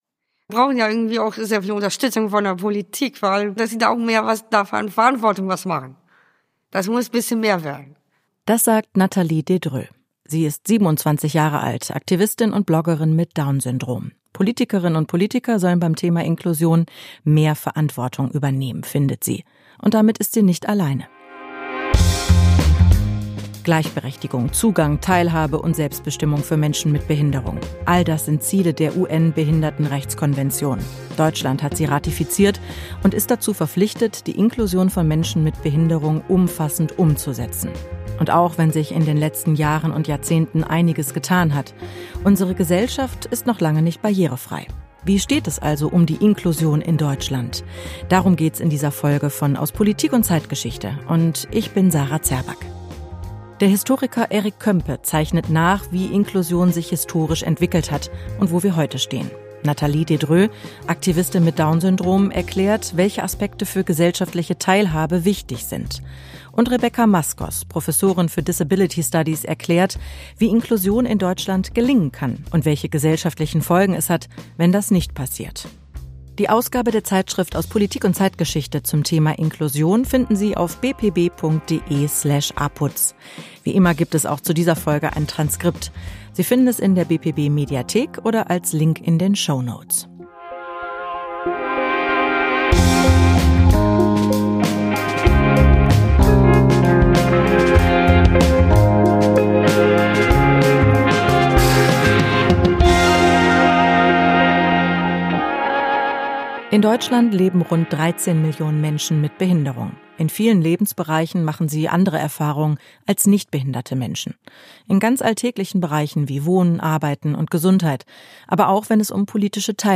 Über den Zustand der Inklusion in Deutschland spricht Moderatorin